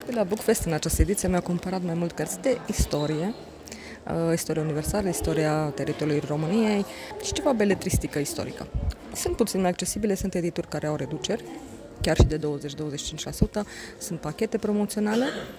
Șoferi și pietoni, deopotrivă, critică modul în care este semaforizat orașul reședință al județului Mureș, printre puținele din țară fără șosea de centură.